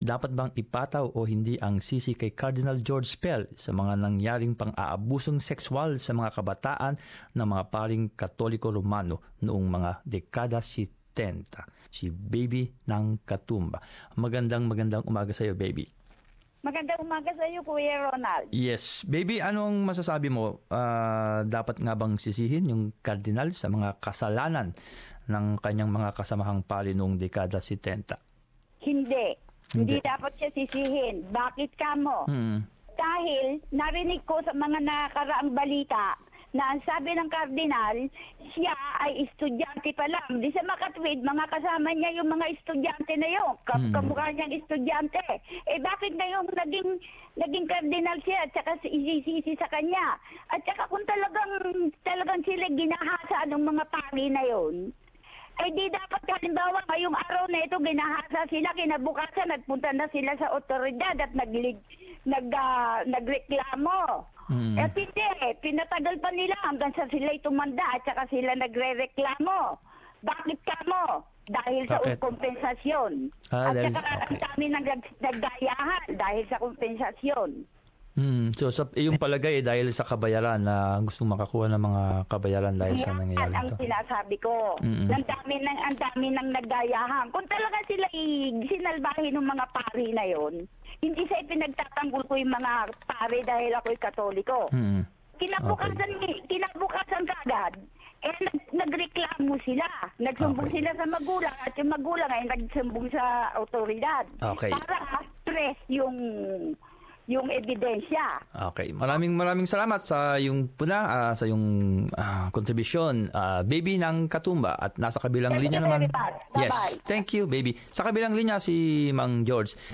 Talkback Results: Many callers are more inclined to believe that as the highest-ranking leader of the Catholic Church in Australia, Cardinal George Pell should be held responsible for the alleged sexual abuses committed by erring priests during the '70s.